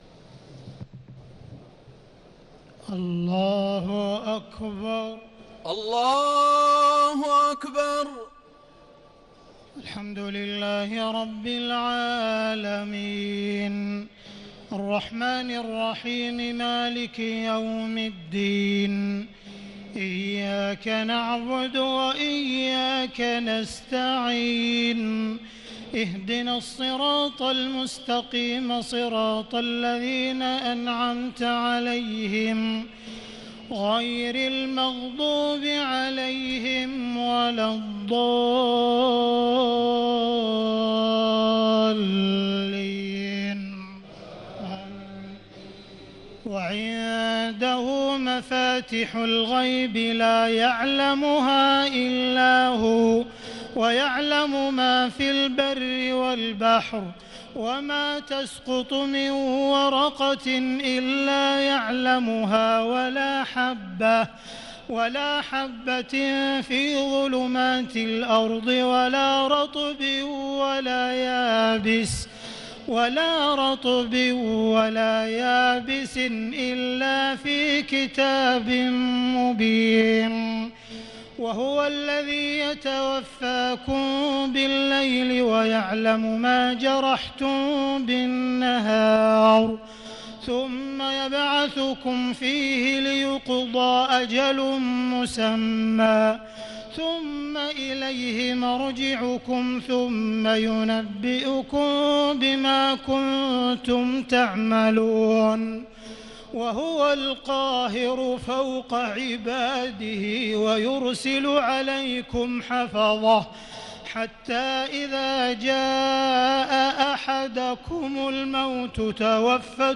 تهجد ليلة 27 رمضان 1439هـ من سورة الأنعام (59-111) Tahajjud 27 st night Ramadan 1439H from Surah Al-An’aam > تراويح الحرم المكي عام 1439 🕋 > التراويح - تلاوات الحرمين